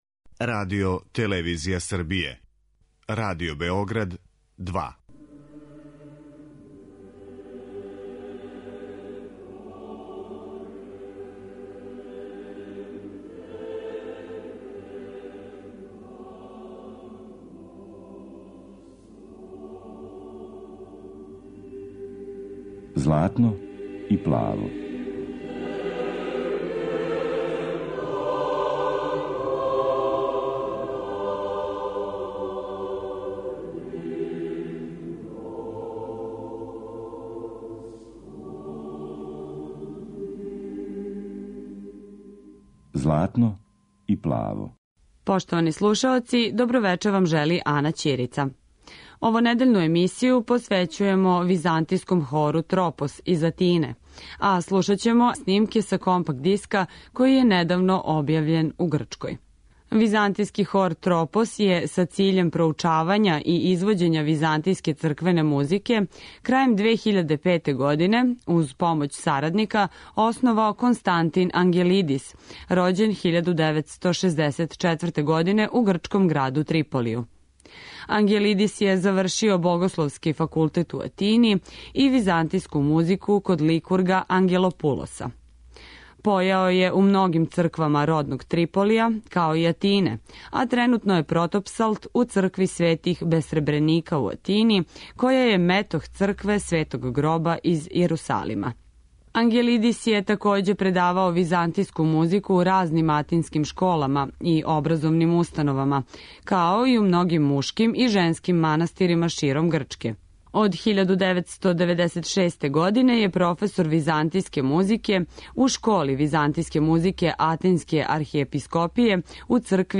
Овонедељну емисију посвећујемо Византијском хору 'Тропос' из Атине.
Емитоваћемо снимке са концерта одржаног 17. октобра 2014. године у новосадској Синагоги, који је обухватио остварења мајстора црквене музике од 13. до 20. века.